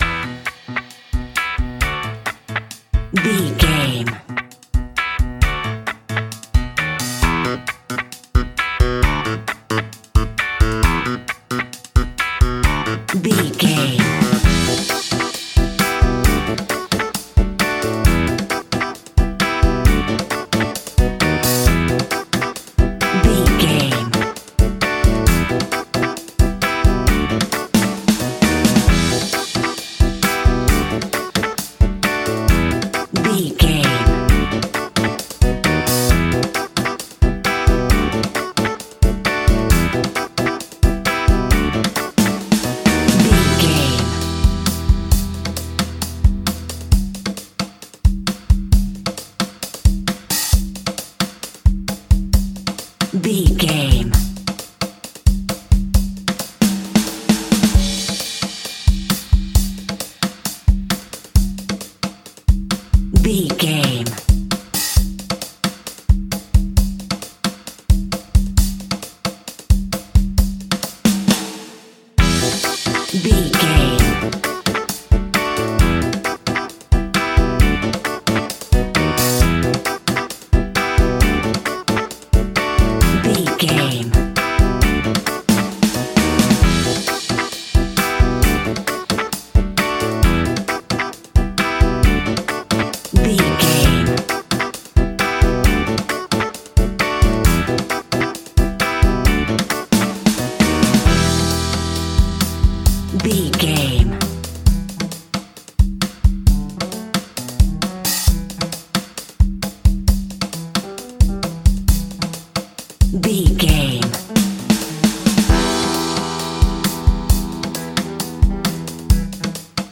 A groovy piece of upbeat Ska Reggae!
Aeolian/Minor
Fast
reggae instrumentals
laid back
chilled
off beat
drums
skank guitar
hammond organ
percussion
horns